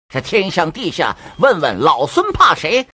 Legendäre Wukong-Stimm-KI
Text-zu-Sprache
Raue Stimme